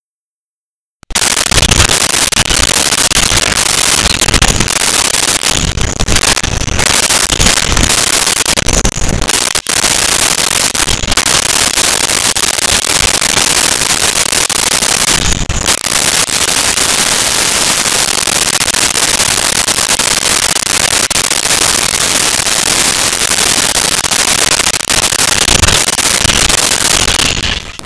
Parrots Squawking
Parrots Squawking.wav